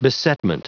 Prononciation du mot besetment en anglais (fichier audio)
Prononciation du mot : besetment